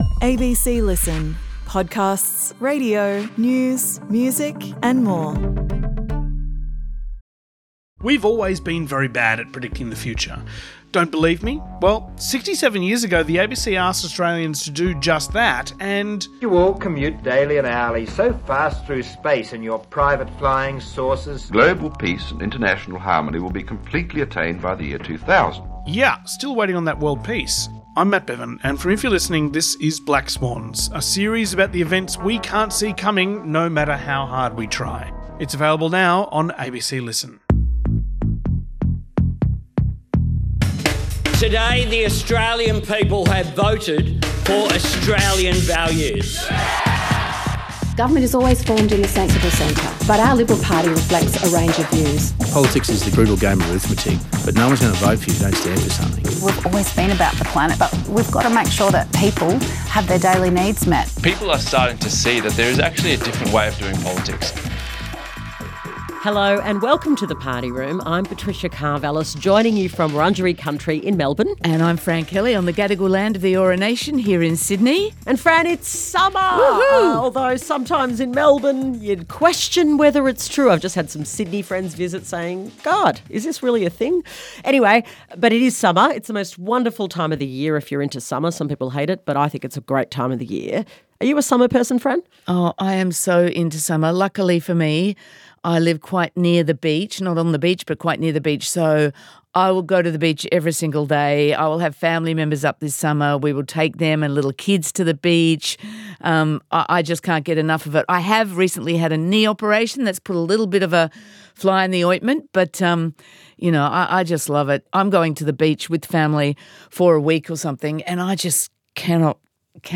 From Monday to Wednesday, Patricia Karvelas will chat to a rotating cast of the ABC's biggest political journalists about the latest news.